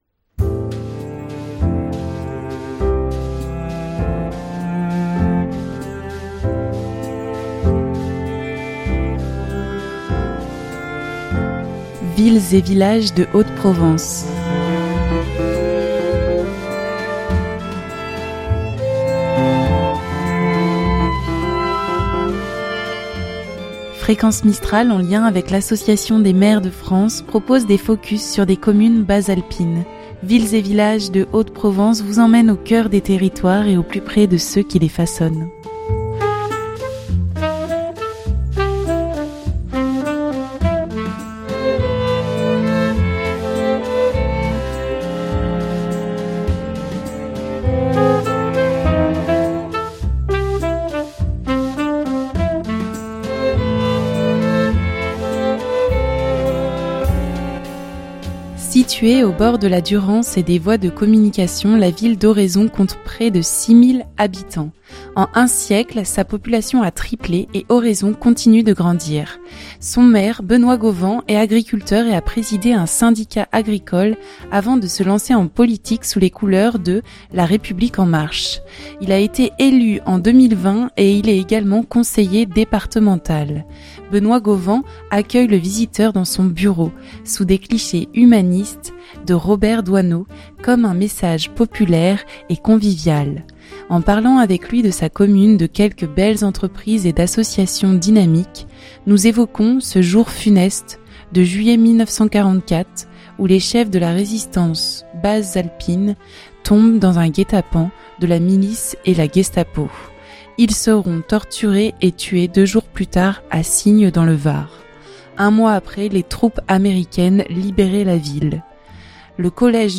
Benoit Gauvan accueille le visiteur dans son bureau sous des clichés humanistes de Robert Doisneau comme un message populaire et convivial. En parlant avec lui de sa commune, de quelques belles entreprises et d’associations dynamiques nous évoquons ce jour funeste de juillet 1944 où les chefs de la Résistance bas-alpine tombent dans un guet-apens de la Milice et la Gestapo.